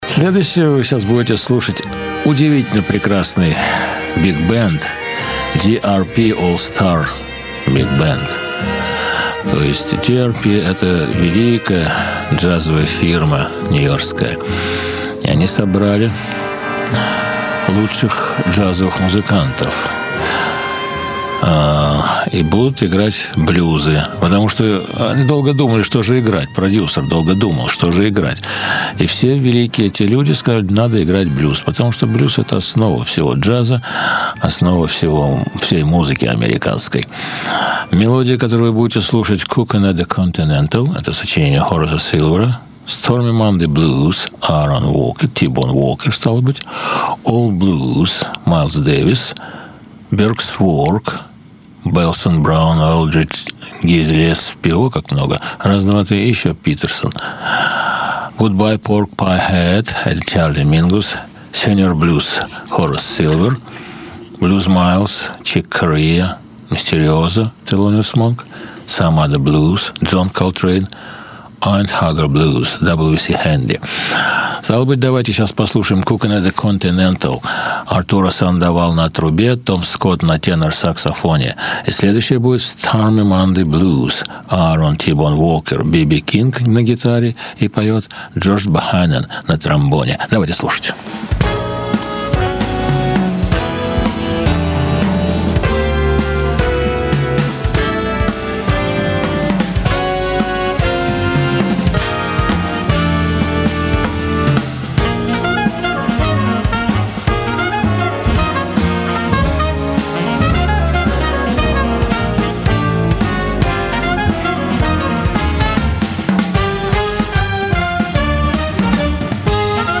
Биг Бенд играет блюзы (нон стоп).